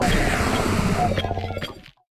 Cri d'Épine-de-Fer dans Pokémon Écarlate et Violet.